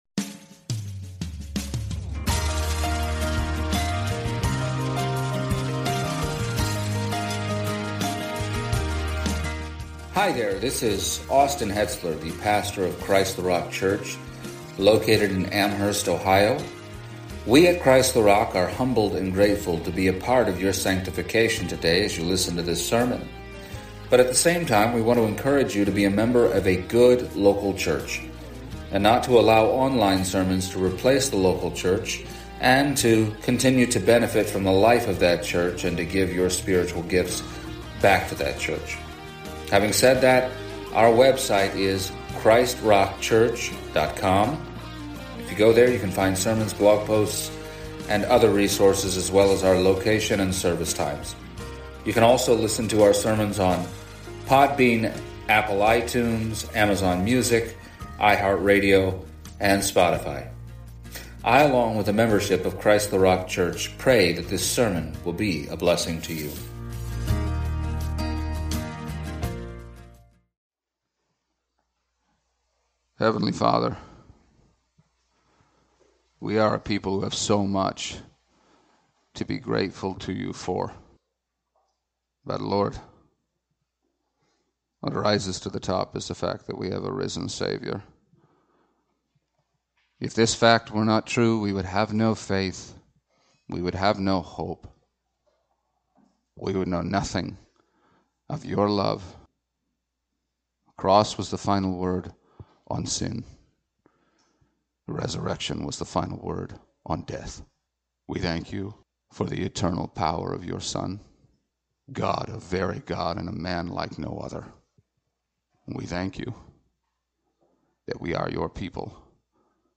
Easter 2025 Passage: Luke 24 Service Type: Sunday Morning Happy Resurrection Sunday!